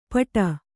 ♪ paṭa